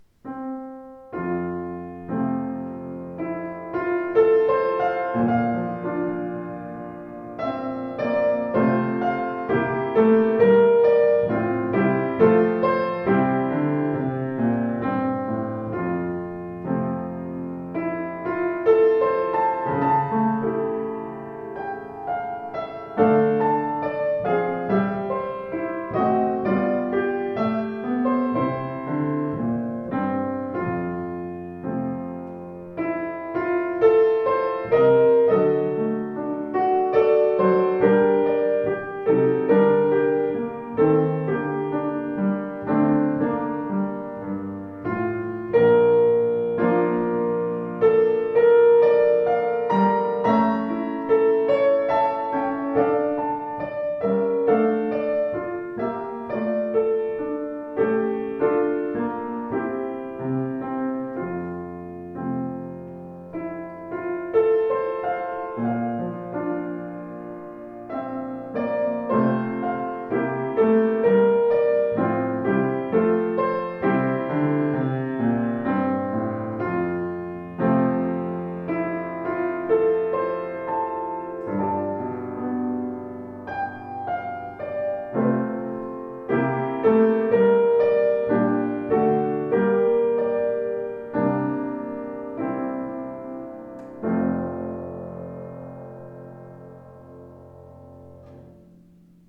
Voller, gestaltungsfähiger Klang, angenehm flüssige Spielart.